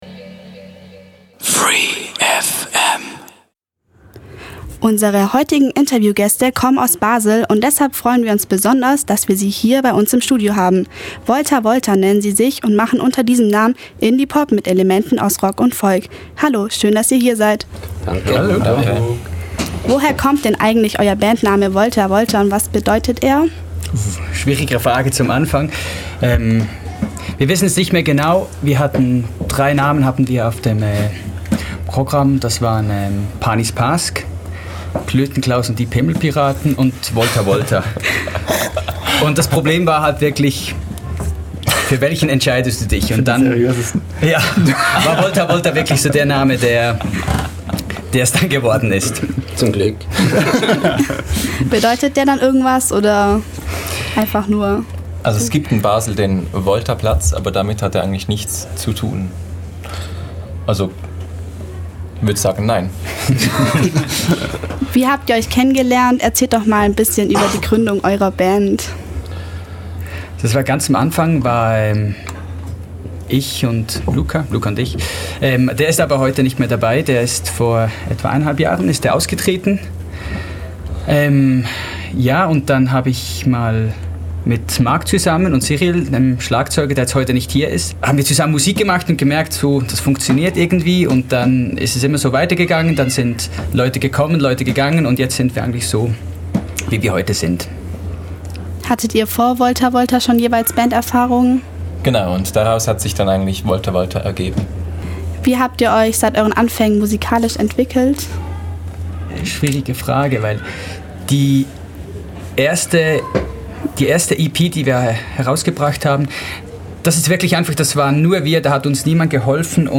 Interview mit der Band Volta Volta
voltavolta-interview1.mp3